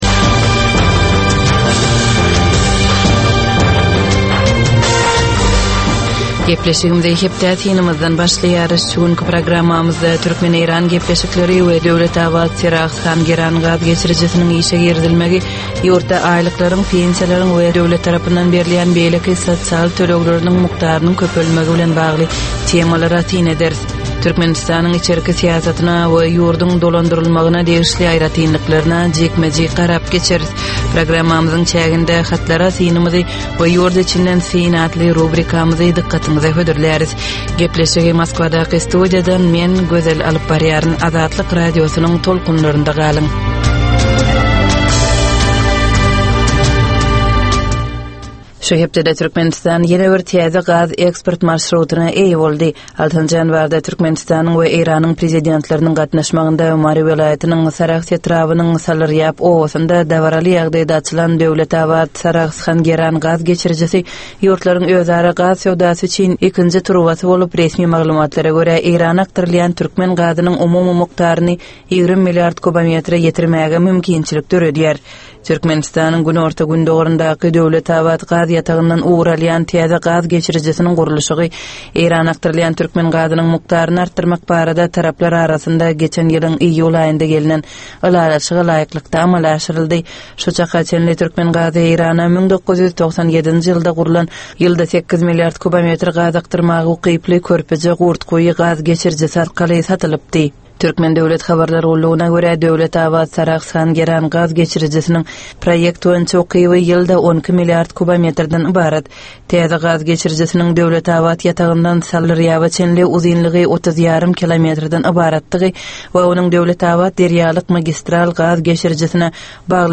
Tutuş geçen bir hepdäniň dowamynda Türkmenistanda we halkara arenasynda bolup geçen möhüm wakalara syn. 25 minutlyk bu ýörite programmanyň dowamynda hepdäniň möhüm wakalary barada gysga synlar, analizler, makalalar, reportažlar, söhbetdeşlikler we kommentariýalar berilýär.